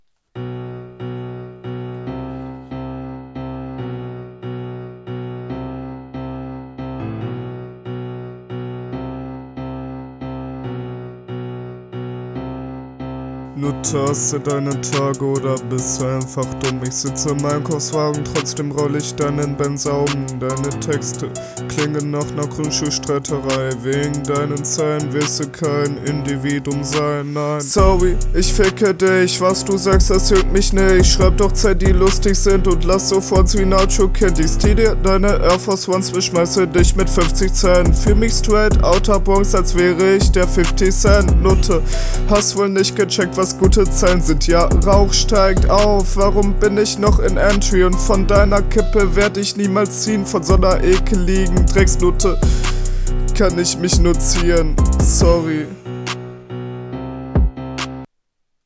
Stimme zu laut gemischt, Störgeräusch permanent im Hintergund zu hören.